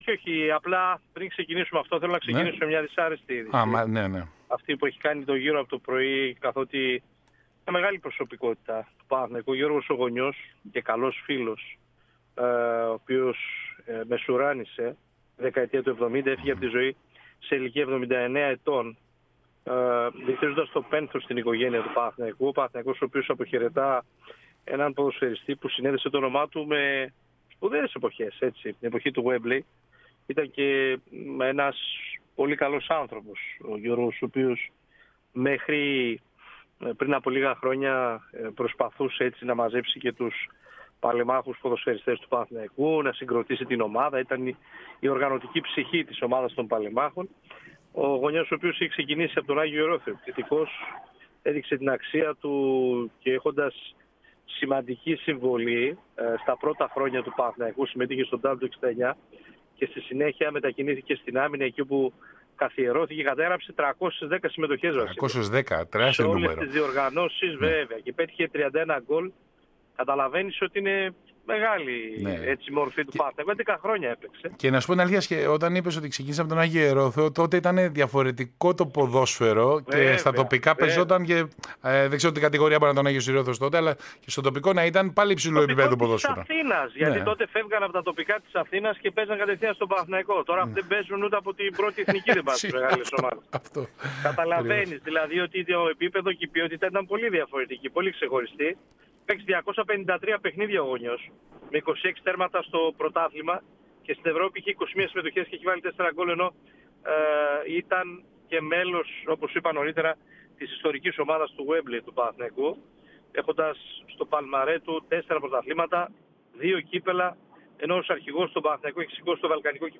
μίλησε στην μεσημβρινή ραδιοεφημερίδα